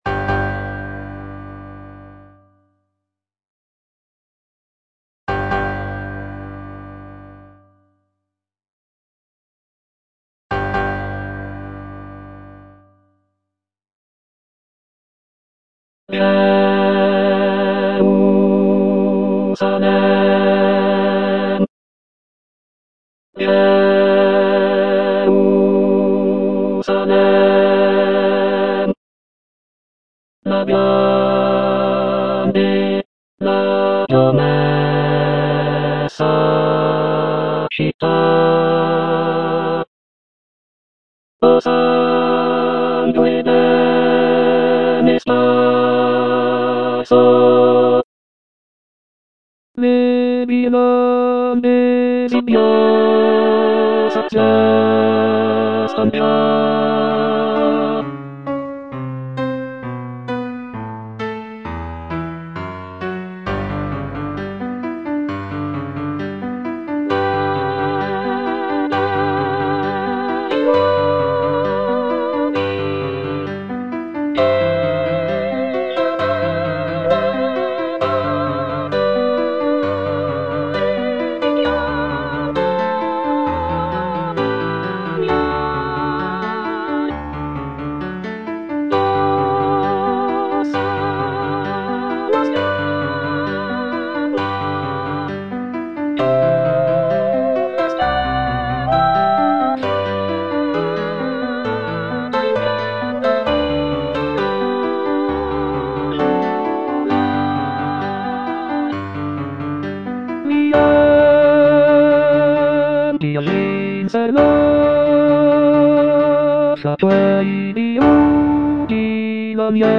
The music captures the solemn and majestic procession of the Lombard Crusaders as they embark on their journey to the Holy Land. The powerful and emotional choral writing, along with the grand orchestration, creates a sense of reverence and anticipation for the epic quest ahead.